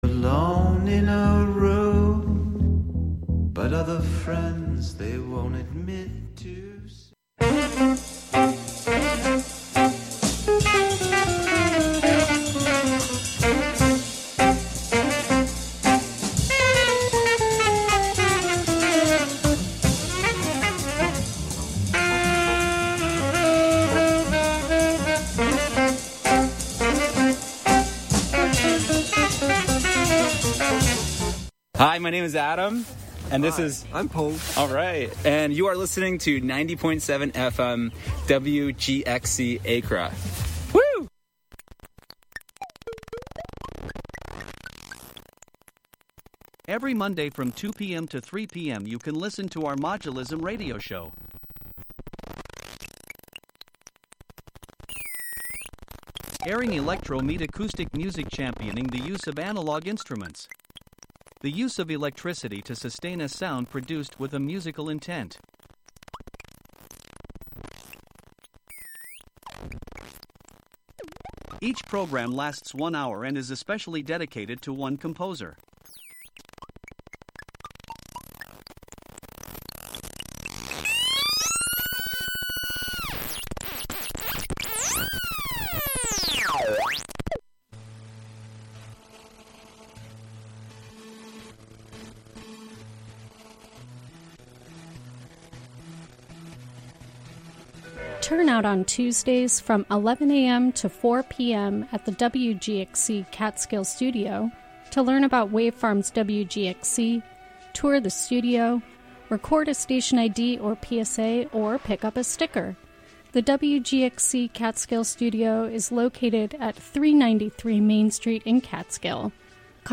This month I explore tracks made in and of the kitchen, with machines and utensils, but also just in the space itself.
To extend this idea into practice, other non-human forms and systems will co-host the show, speak, and sing.